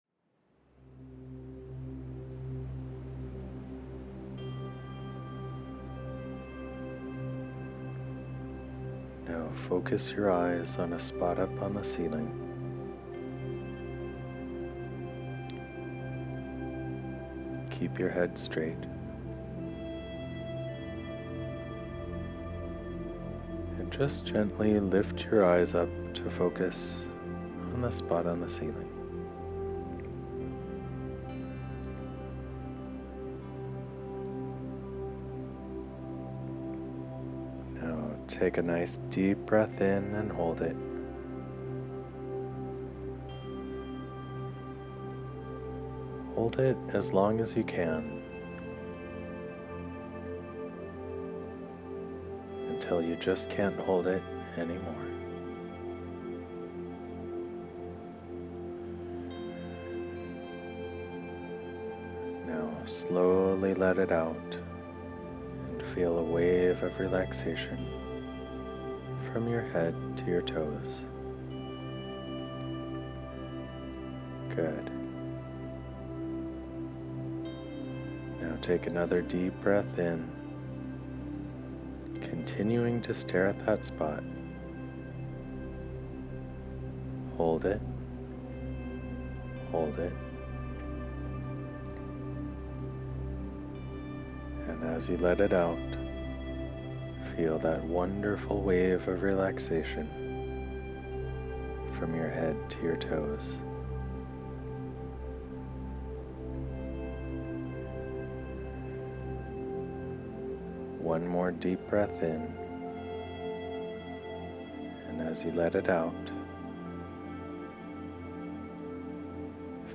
HIGHER SELF EXPERIENCE MEDITATION